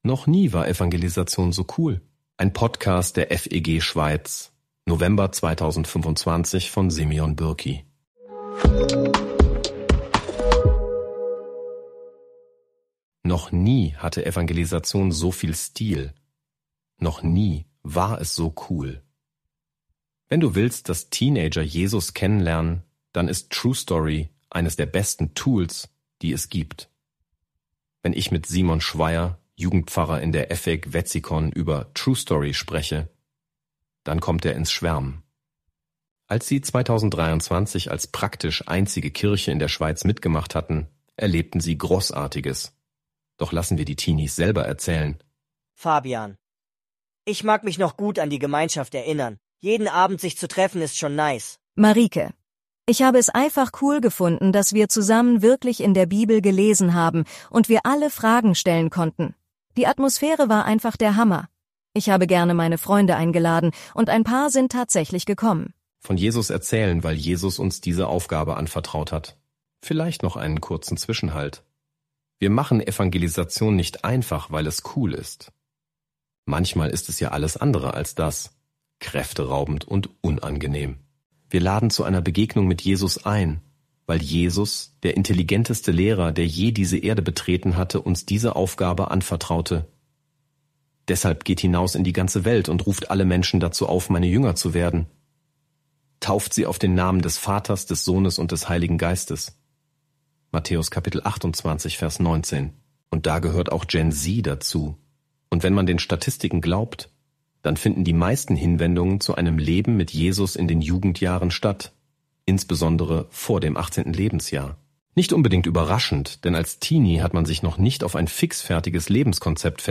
Doch lassen wir die Teenies selber erzählen: